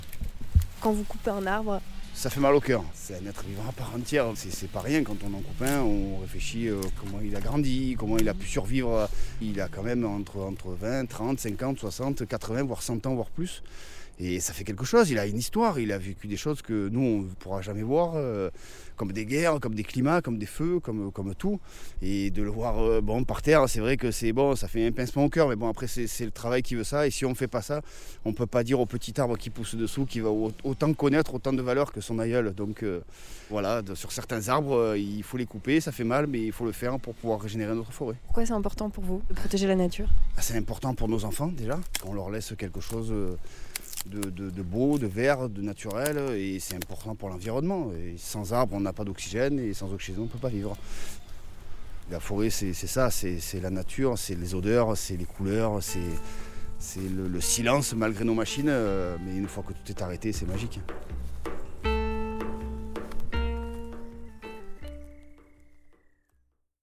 En attendant, je partage avec vous une toute petite émission entendue il n’y a pas longtemps à la radio. Pour tous ceux qui sont confinés en ville et en appartement, voici une histoire d’arbres.
Je vous laisse donc en compagnie de ce forestier, amoureux des arbres.